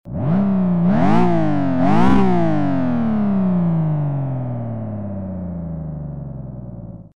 It runs this sample through a series of filters which simulate different aspects of the car's engine.
FZ5_car_sound_test.mp3 - 95.2 KB - 319 views